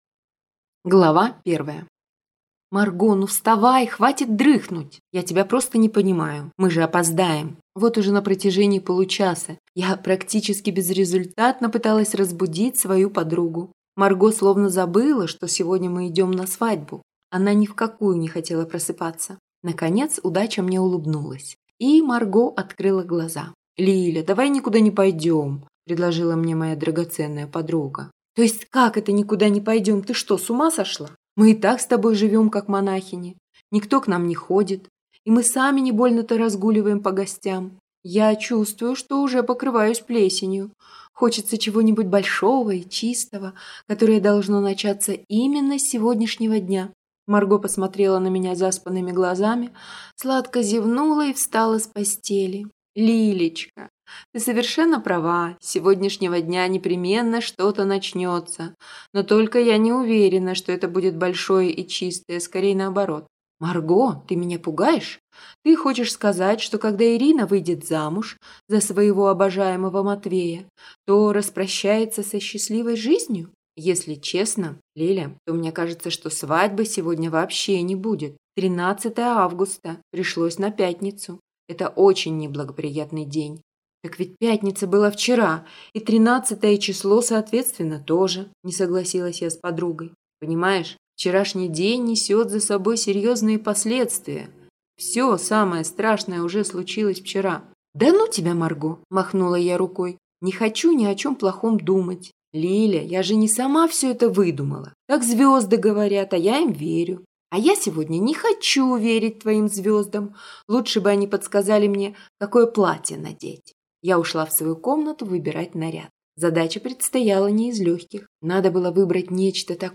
Аудиокнига Гороскоп для невесты | Библиотека аудиокниг